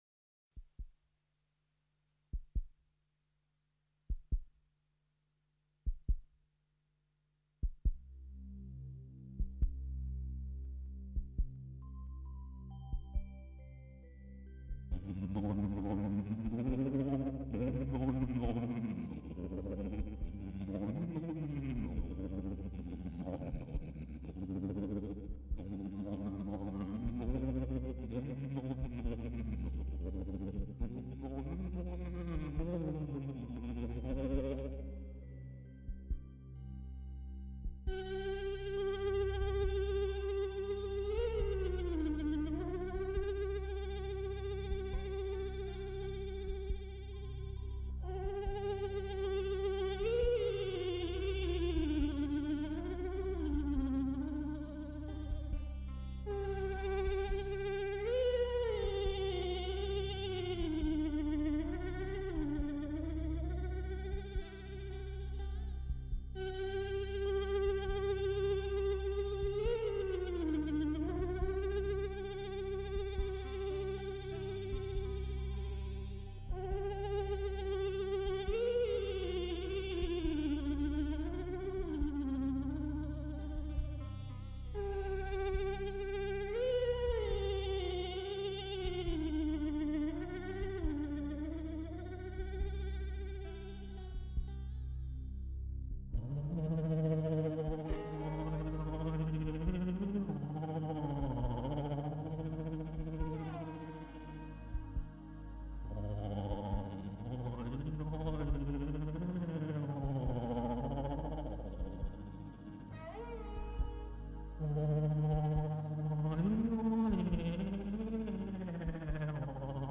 随行人员用普通的录音机录下的声音